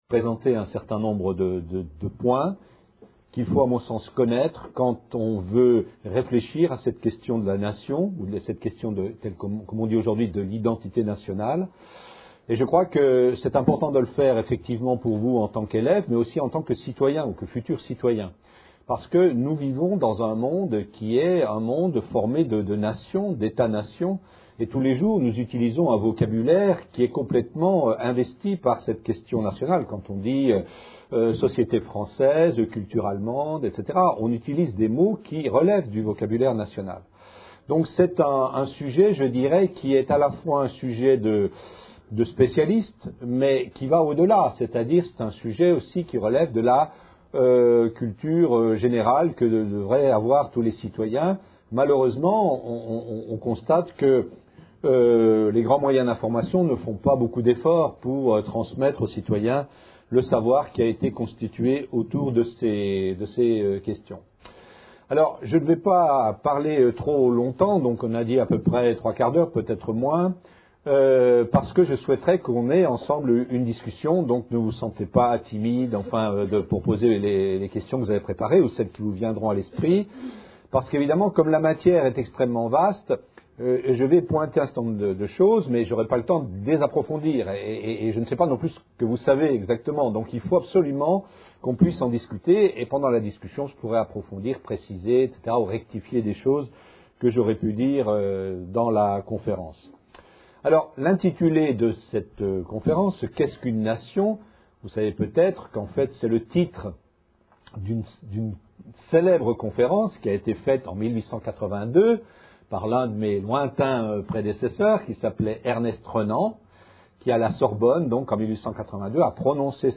Une conférence de l'UTLS au Lycée Qu'est ce qu'une nation par Gérard Noiriel, historien Lycée Montesquieu (33 Bordeaux)